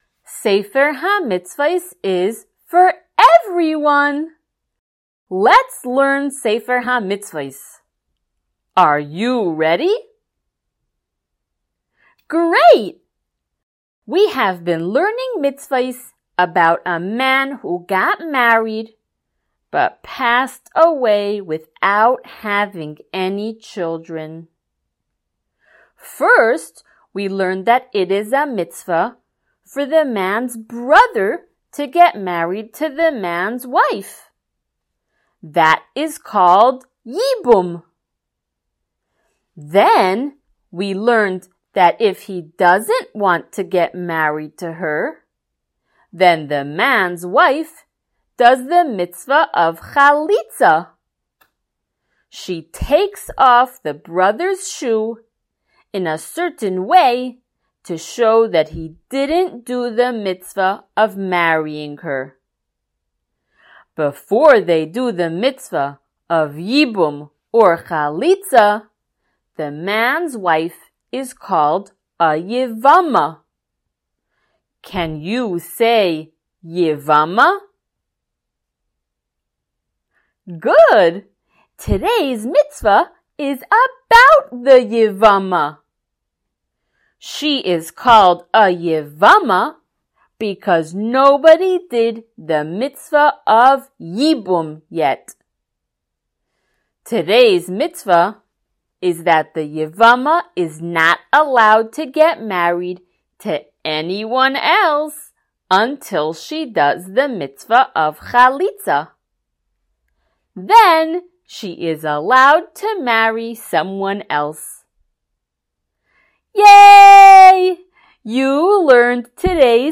SmallChildren_Shiur084.mp3